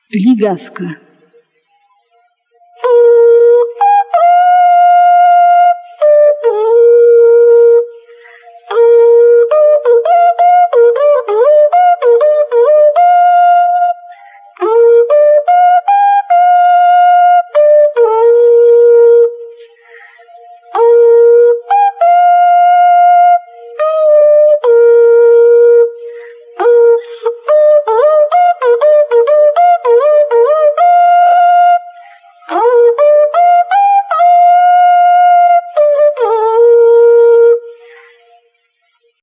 Na Podlasiu szczególną rolę odgrywały ligawy – długie rogi sygnałowe. Służyły do komunikacji na odległość, zwoływania ludzi i bydła, a także towarzyszyły praktykom obrzędowym.
ligawy
ligawki.mp3